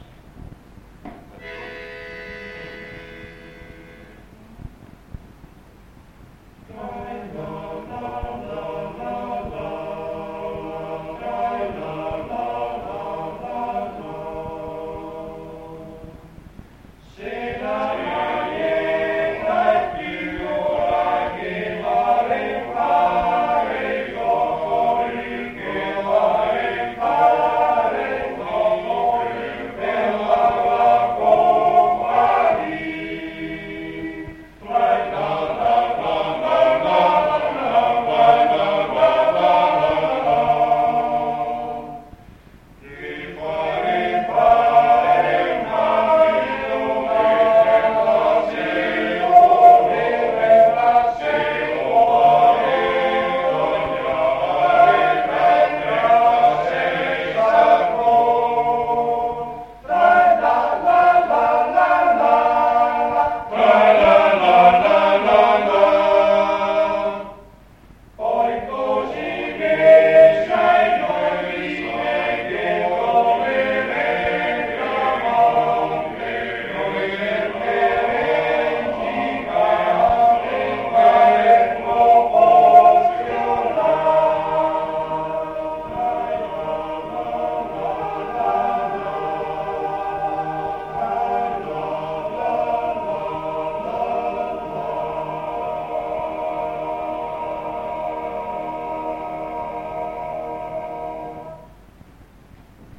A.8.1.12 - Se la Marieta è piccola (Coro della SAT, prove, 1958 o 1959)